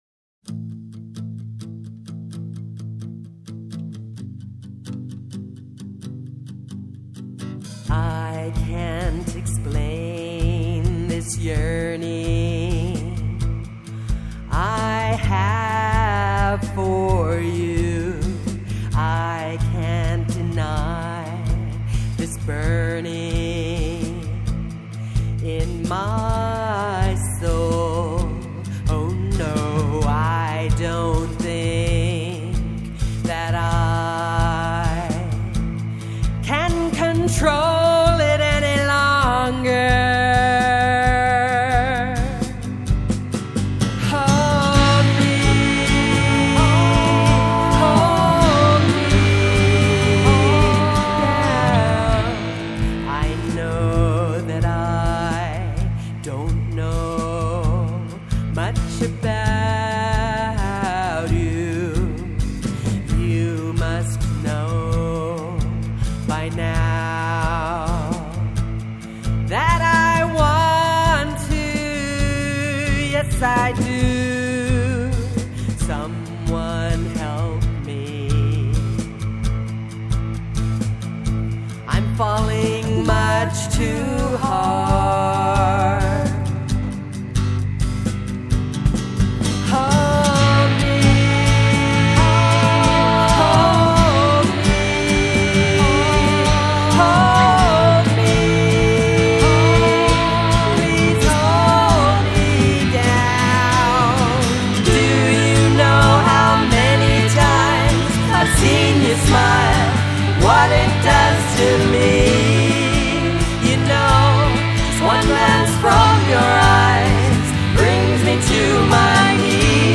Vocals, Acoustic Guitar, Electric Guitar, Keyboards, Drums
So, for you musicians, the chords are A and G.